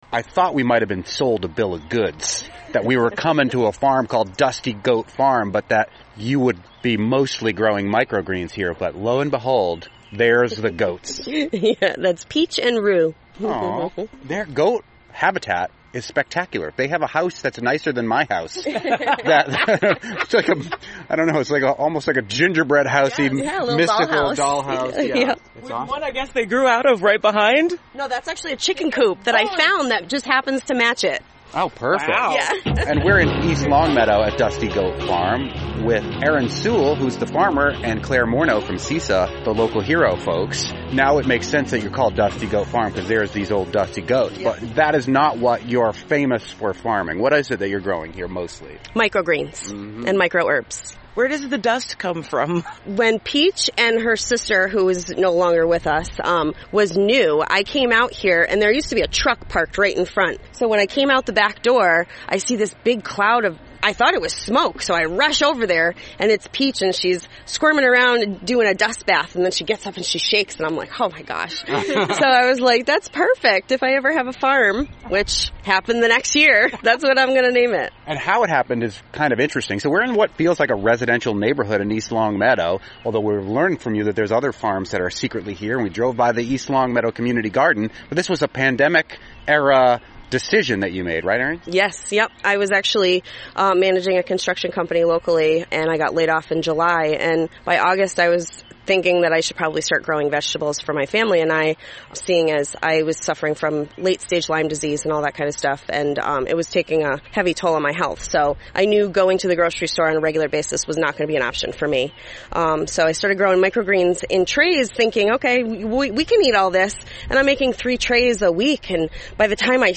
NEPM "Fabulous 413" interviews